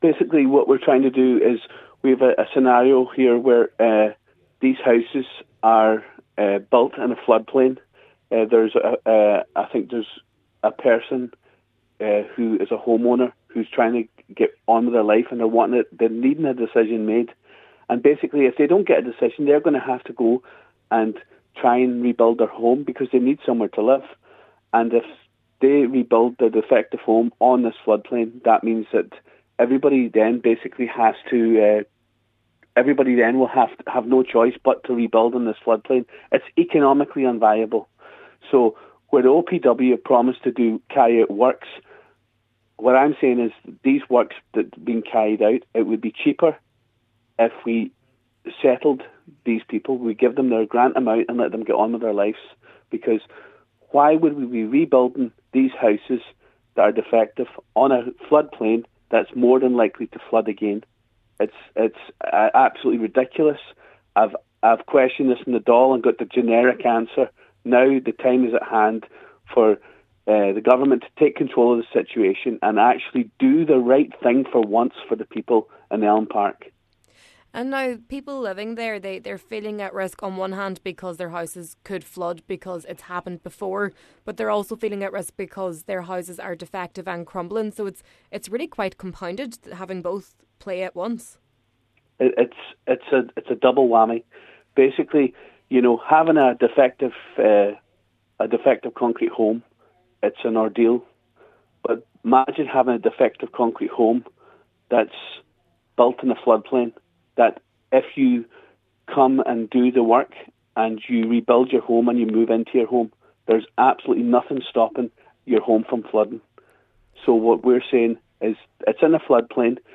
As it stands, they cannot get flood insurance and Deputy Ward asks who would rebuild their home only for it to become destroyed again: